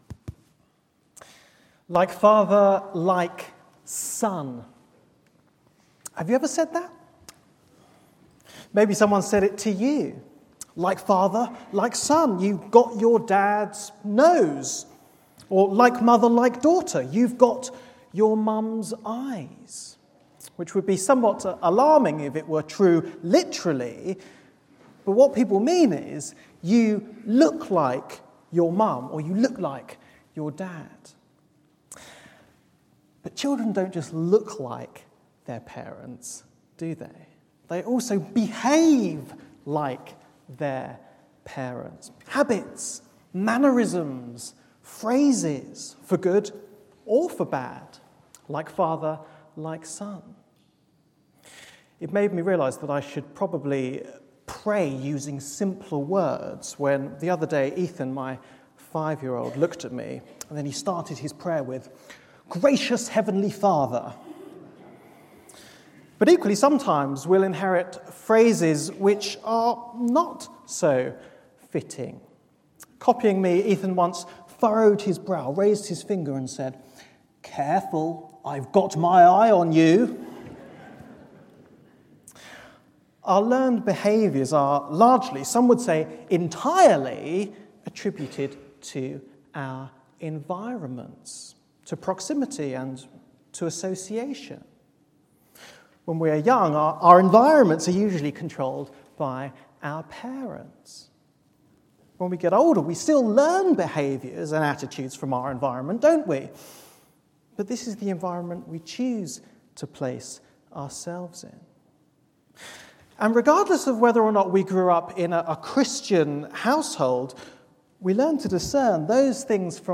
speaking